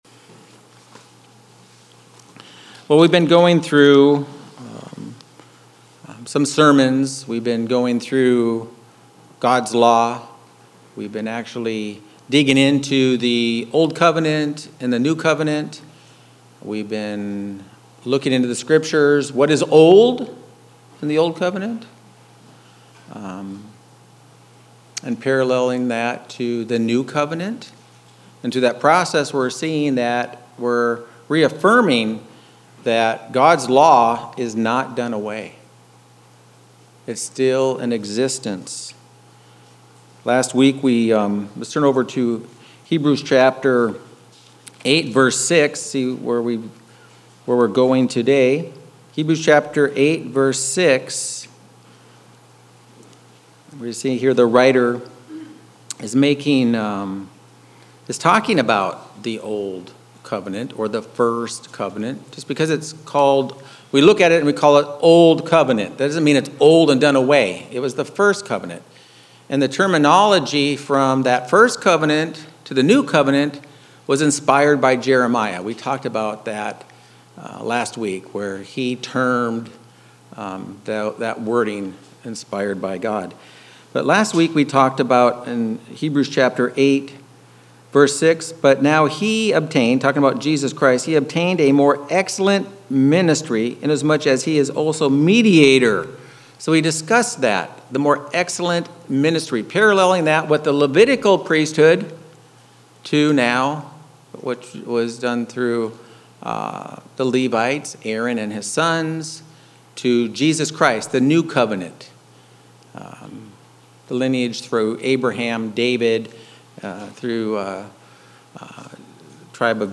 Given in Phoenix East, AZ